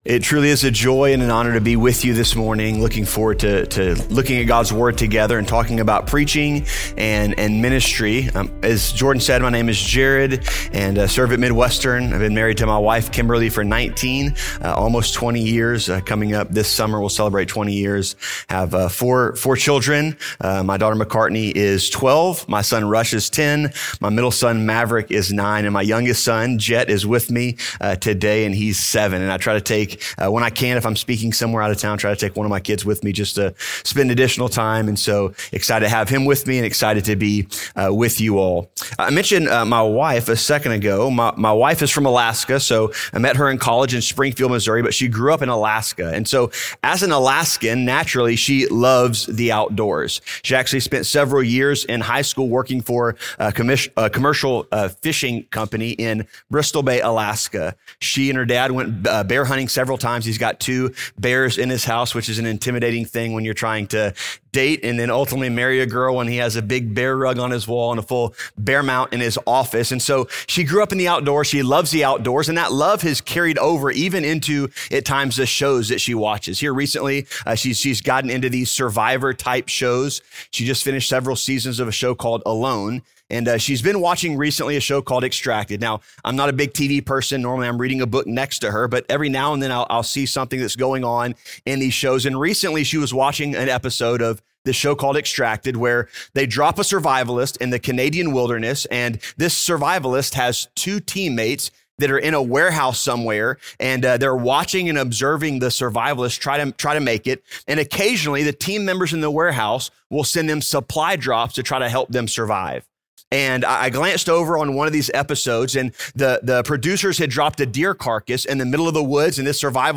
gives a recent talk from a Midwestern Institute for Preaching and Preachers Workshop.